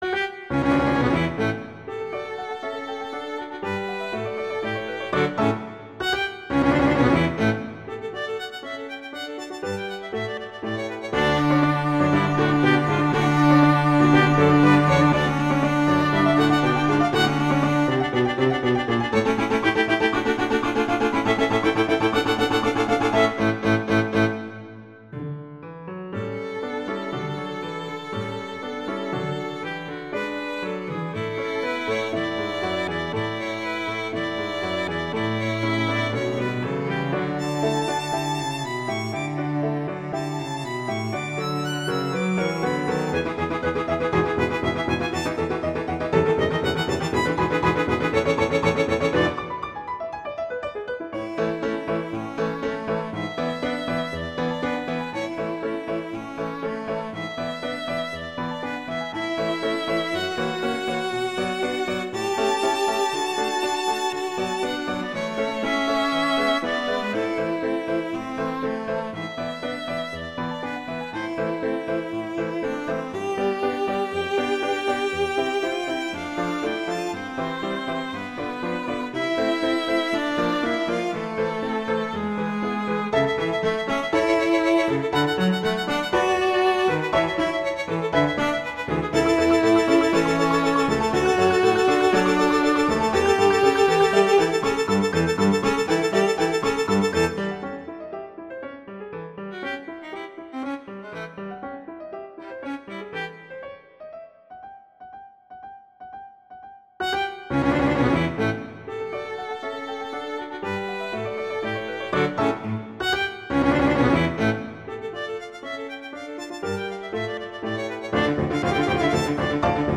classical
D major, A major